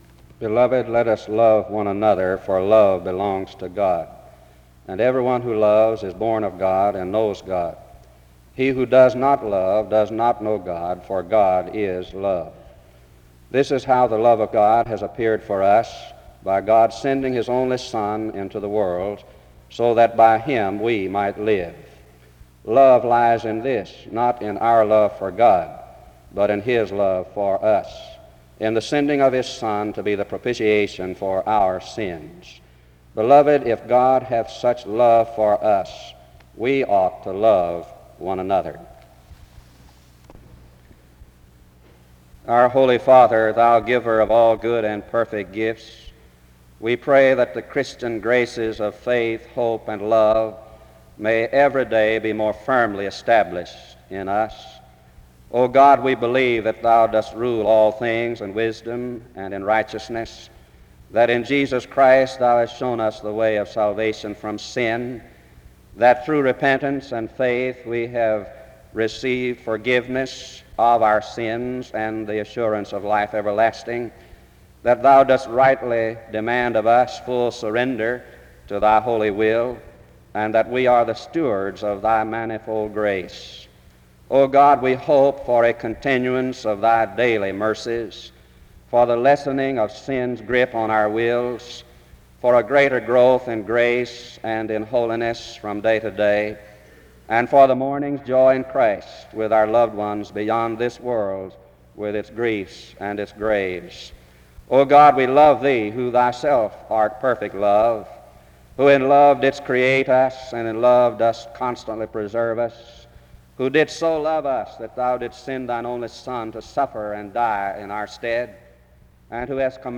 SEBTS Chapel
The service begins with a scripture reading from 0:00-0:41. A prayer is offered from 0:42-2:29.